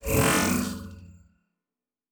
Teleport 4_1.wav